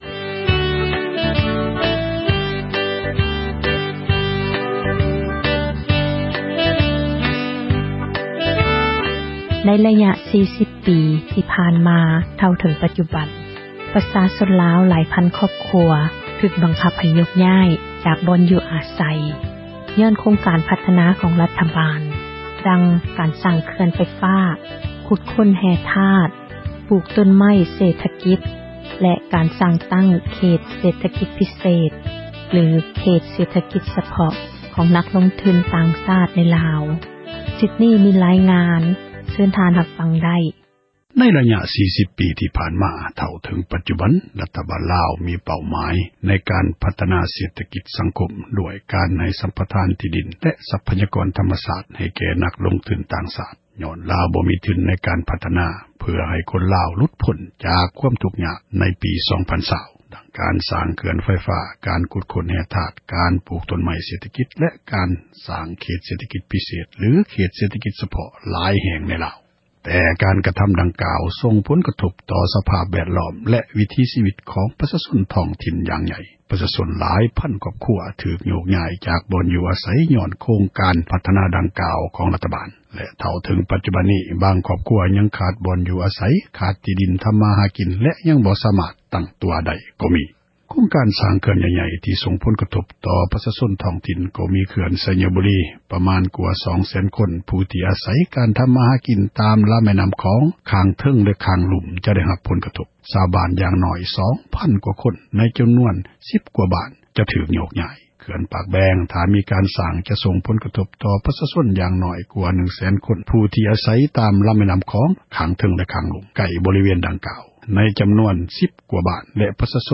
Citizen journalist